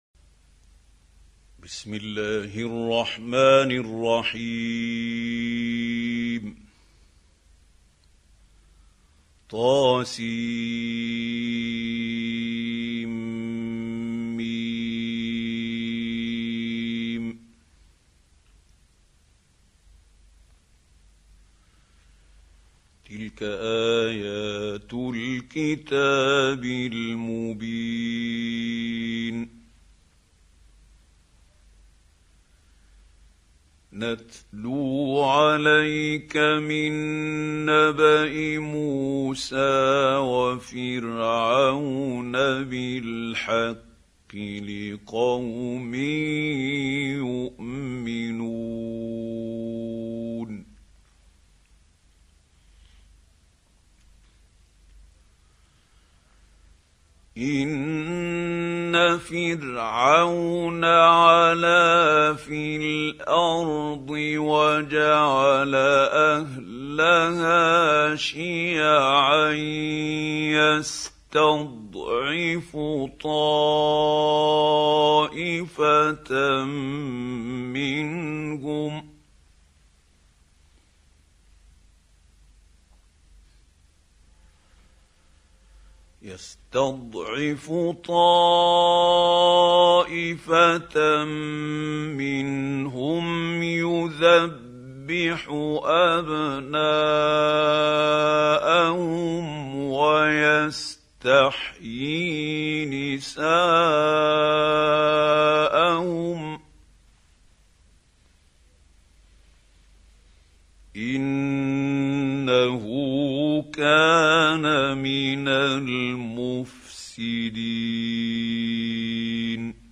Қуръони карим тиловати (Мужаввид) - Халил Ҳусорий